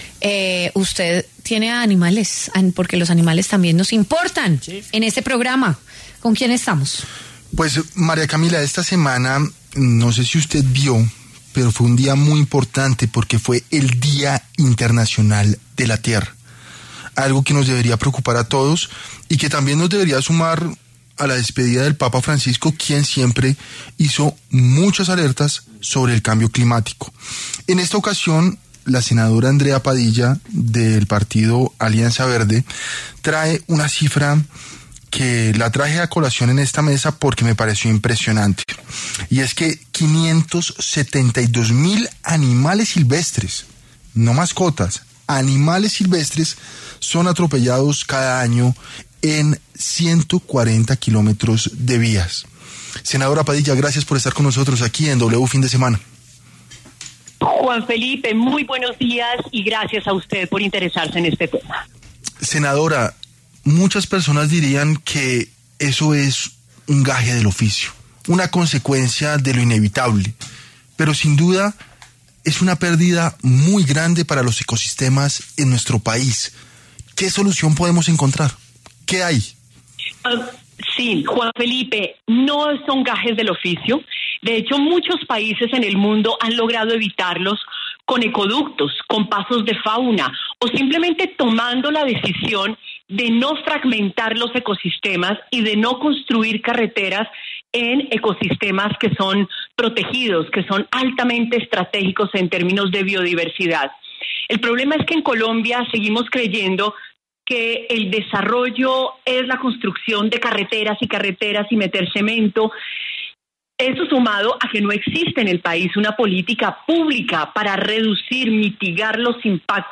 W Fin de Semana habló con la congresista Andrea Padilla, quien entregó cifras de los miles de animales muertos en vías del país.
Andrea Padilla, senadora de la Alianza Verde, habla sobre la pérdida de biodiversidad de especies en el país por atropellamiento en diferentes vías de Colombia.